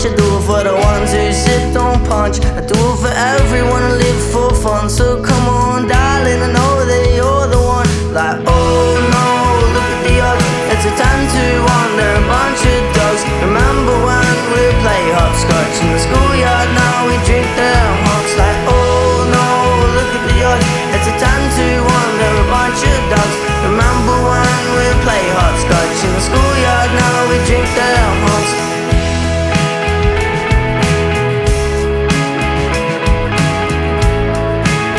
Жанр: Иностранный рок / Рок / Инди
# Indie Rock